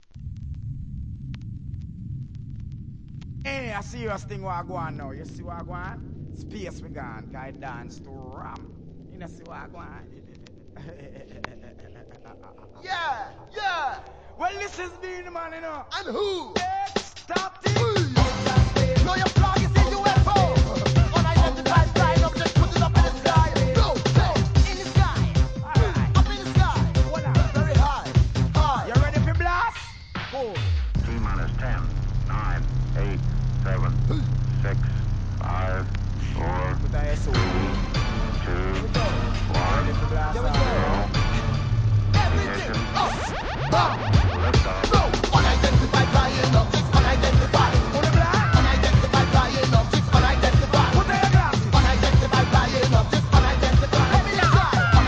どれも起爆剤を抱えた怒キャッチーなソカ・ナンバー!!